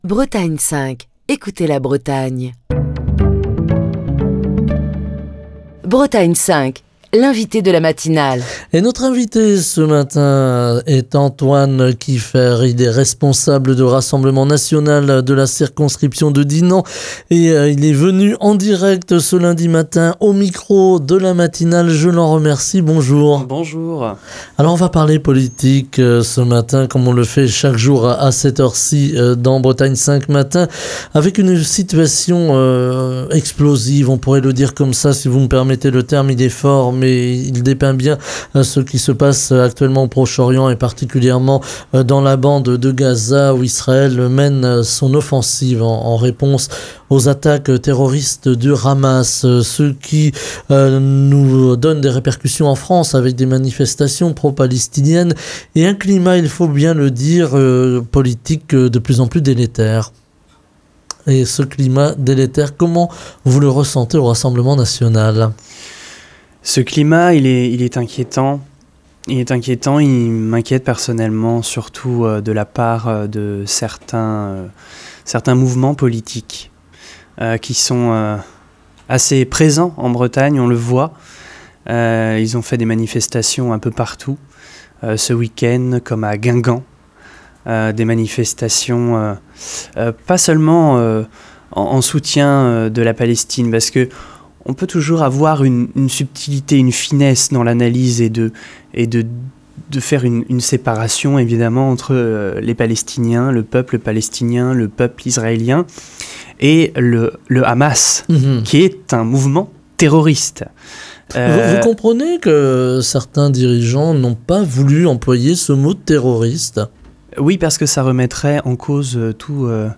Émission du 30 octobre 2023.